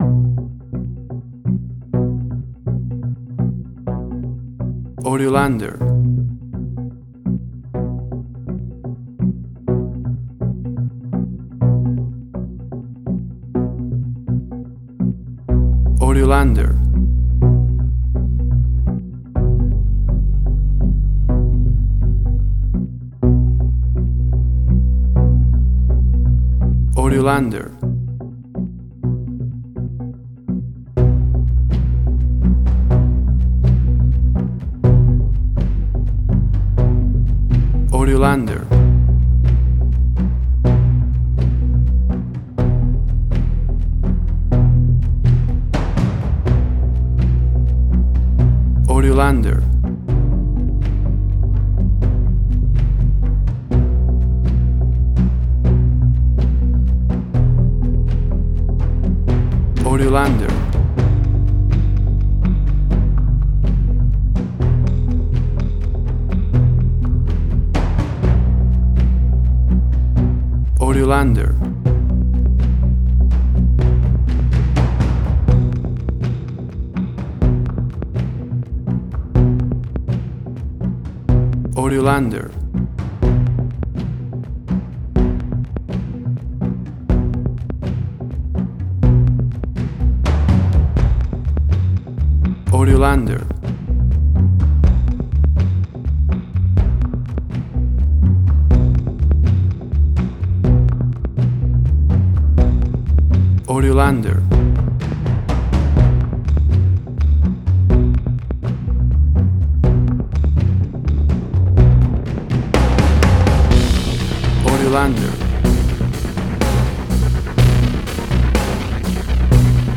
Suspense, Drama, Quirky, Emotional.
WAV Sample Rate: 16-Bit stereo, 44.1 kHz
Tempo (BPM): 124